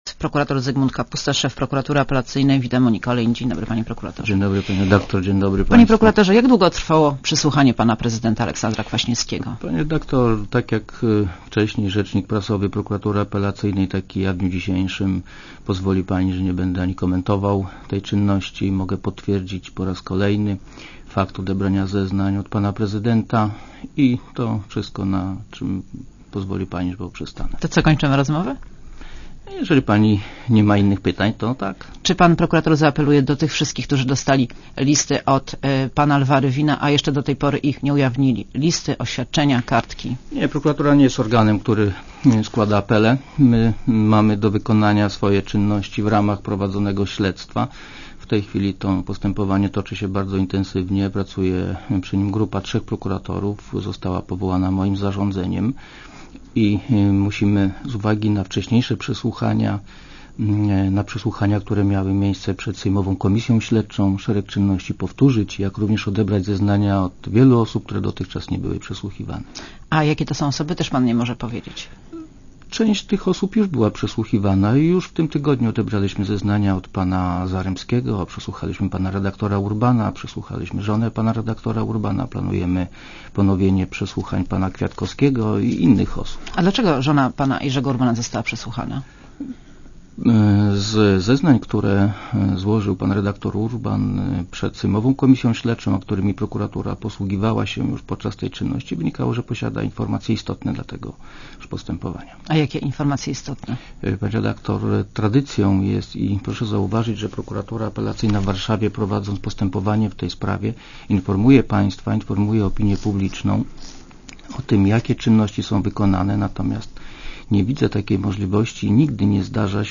Monika Olejnik rozmawia z Zygmuntem Kapustą - szefem prokuratury apelacyjnej